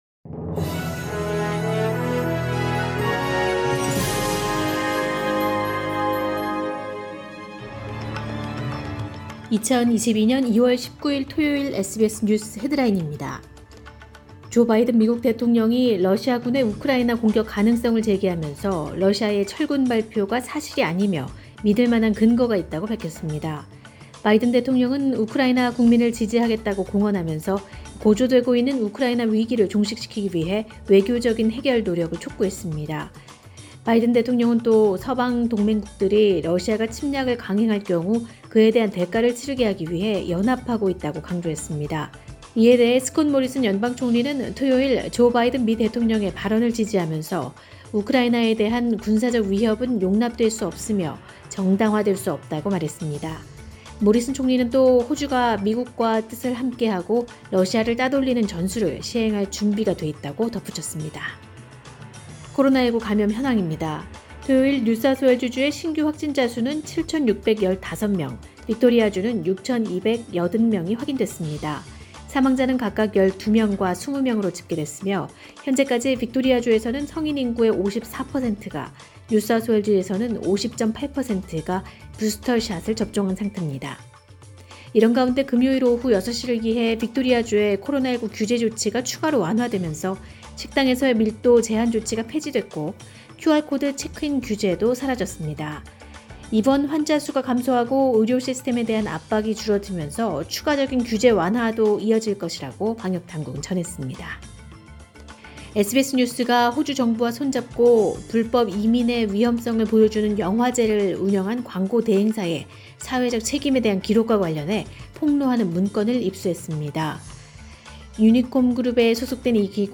2022년 2월 19일 토요일 SBS 뉴스 헤드라인입니다.